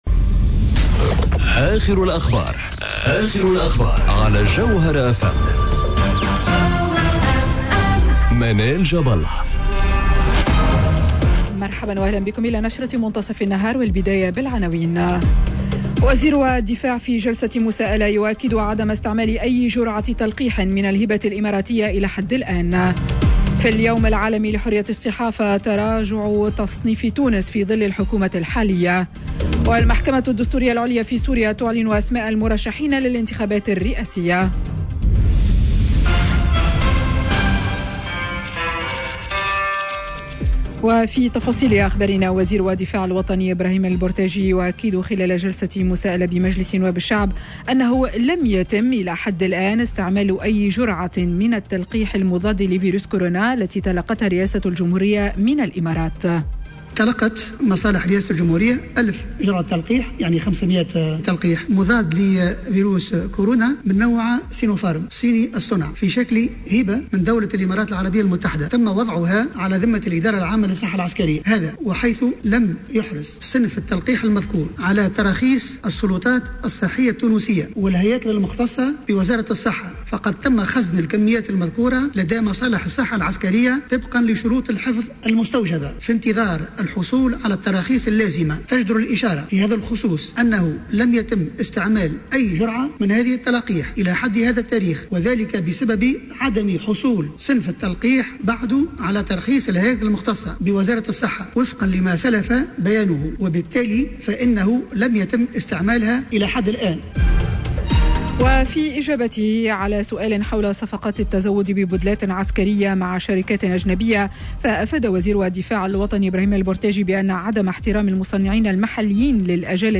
نشرة أخبار منتصف النهار ليوم الإثنين 03 ماي 2021